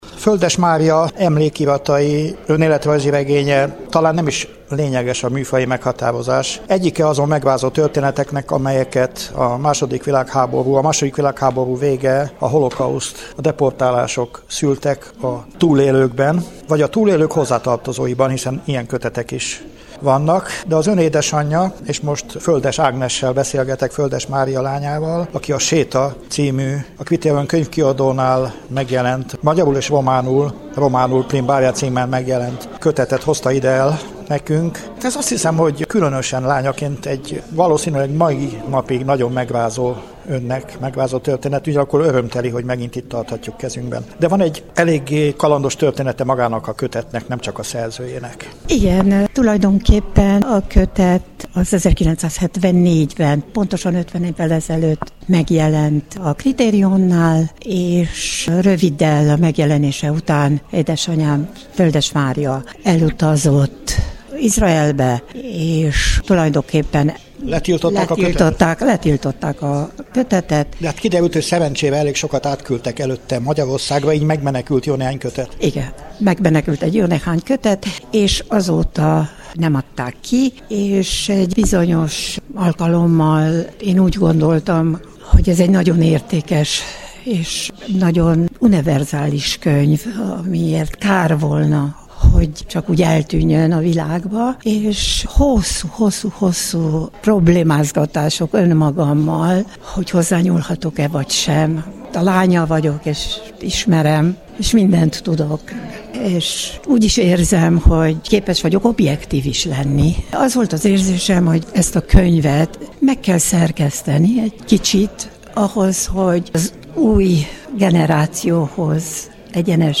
A könyvet a Kolozsvári Zsidó Közösségi Központ épületében is bemutatták.